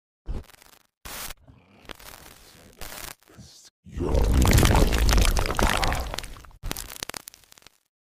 CCTV Camera Recording's Captured